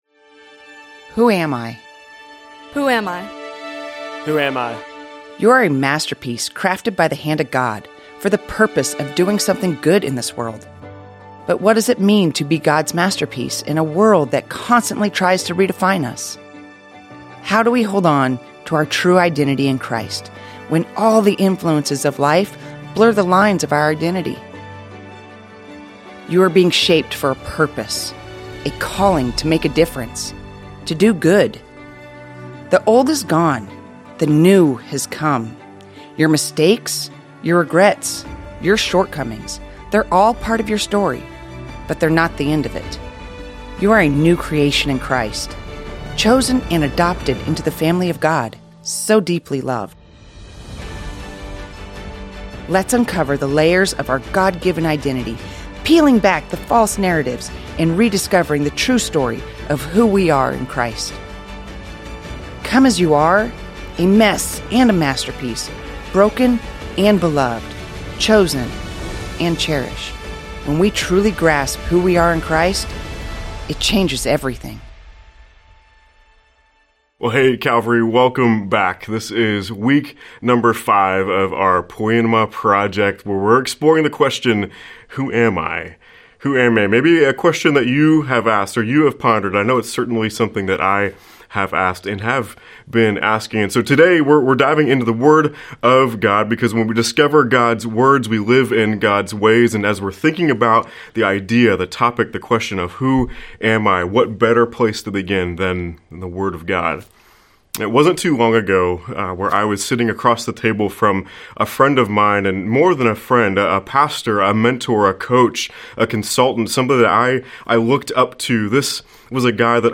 The sermon concludes with a comforting reminder for weary souls that God has chosen them, inviting them into a loving relationship.